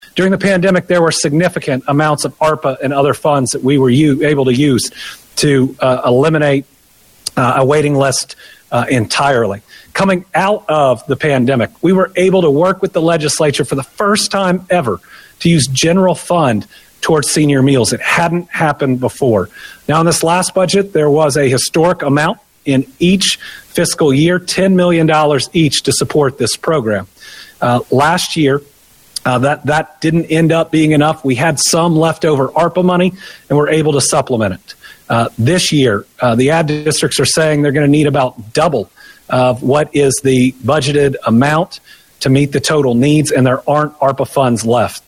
During his Team Kentucky update Thursday afternoon, Governor Andy Beshear confirmed the cuts were coming, noting all belts had to be tightened at least until the Kentucky General Assembly comes into session for the biennium budget — that this is a senior program that didn’t exist in its current form, until the pandemic hit in 2020 and long waiting lists were exacerbated.